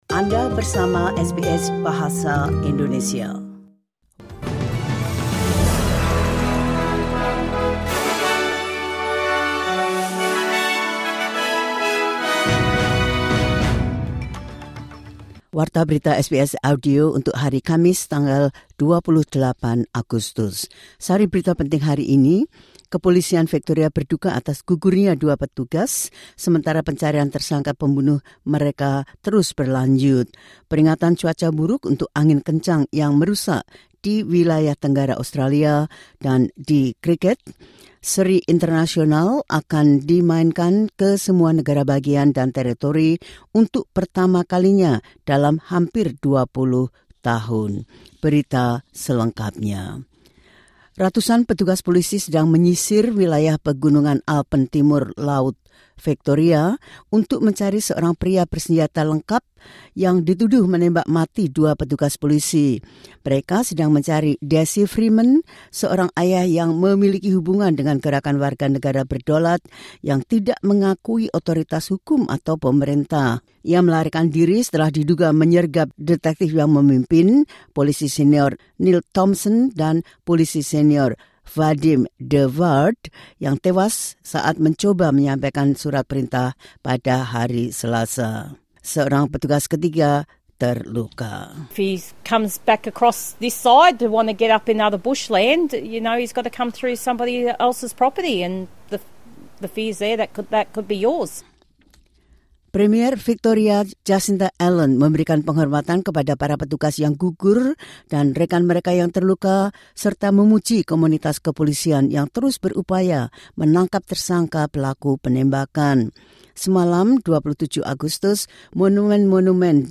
Berita terkini SBS Audio Program Bahasa Indonesia – 28 Agustus 2025
The latest news SBS Audio Indonesian Program – 28 August 2025.